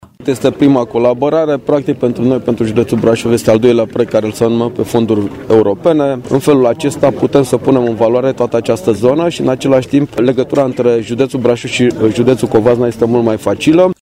Adrian Veștea, președintele CJ Brașov a subliniat că este prima colaborare în domeniul infrastructurii rutiere, între județele Brașov și Covasna: